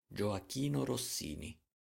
^ Pronunciation: UK: /ˌəˈkn rɒˈsni/,[7][8][9] US: /- rˈ-, rəˈ-, rɔːˈ-/;[8][9][10][11] Italian: [dʒoaˈkiːno anˈtɔːnjo rosˈsiːni]
It-Gioachino_Rossini.oga.mp3